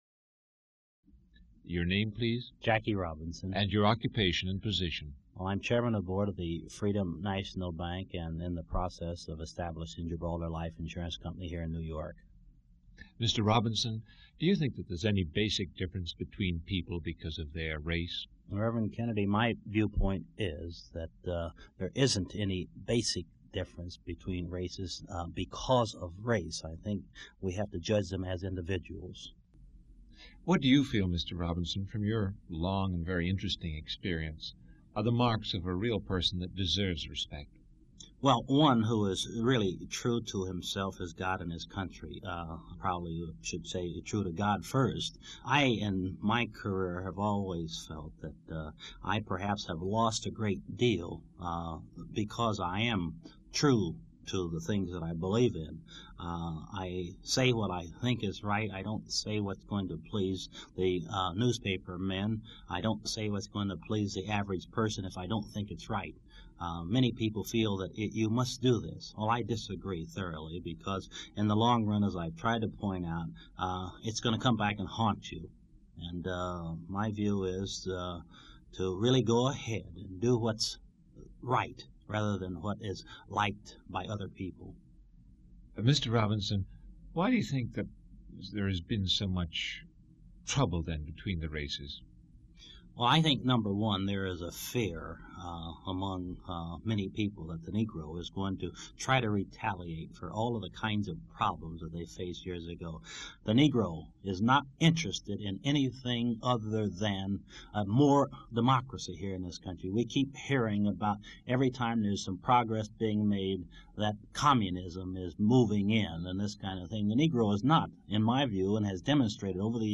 Drawing on his experience in baseball, Jackie Robinson explains his belief that there is no fundamental difference between the races that cannot be overcome by mutual respect and goodwill, 1964.